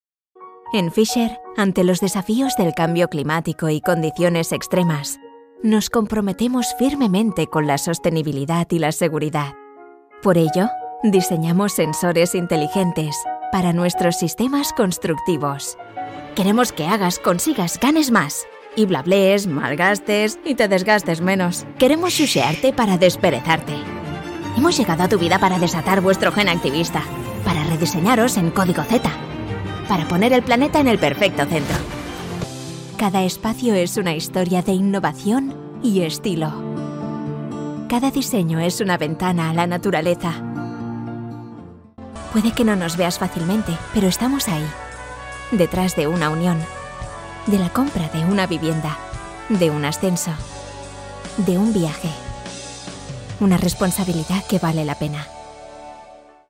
Jong, Natuurlijk, Veelzijdig, Warm, Zacht
Corporate
A versatile voice that can reach audiences with a sweet, natural and friendly vibe, she can also bring an emotional depth, or perhaps a serious and mature tone for business presentations.